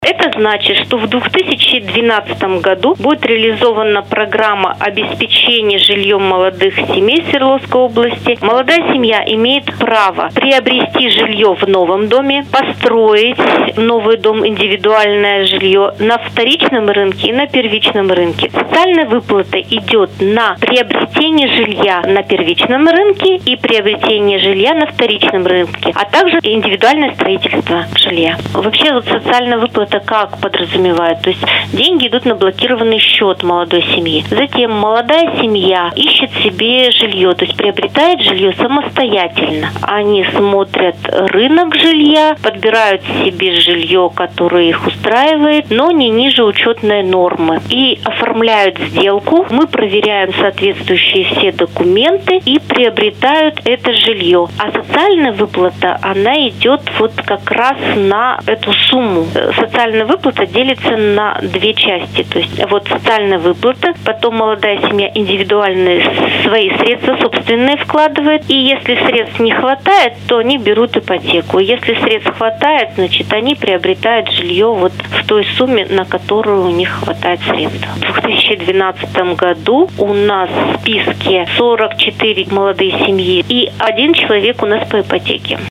«запись с телефона».